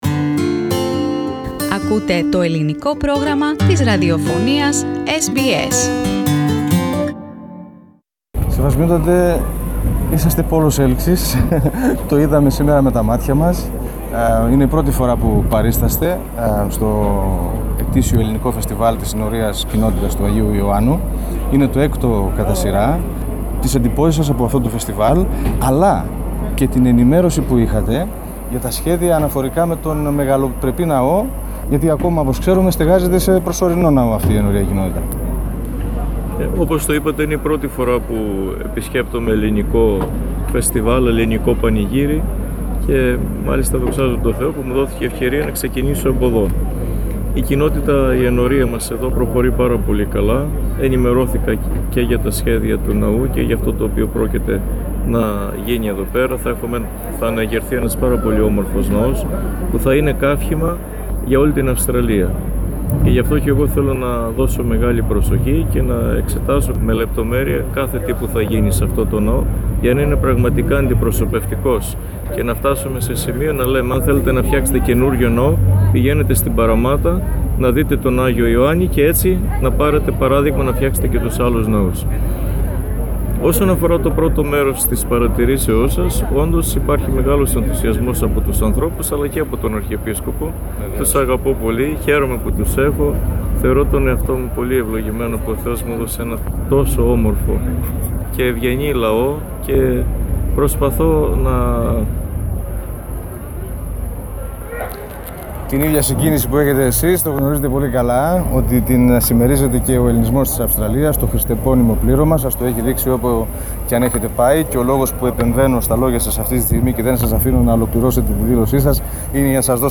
Εμφανώς συγκινημένος ο Αρχιεπίσκοπος Αυστραλίας Μακάριος μίλησε αποκλειστικά στο μικρόφωνο του Ελληνικού Προγράμματος της Ραδιοφωνίας SBS, αναφερόμενος στην αγάπη που εισπράττει, αλλά και στο ταξίδι του στα Ιεροσόλυμα και το Φανάρι.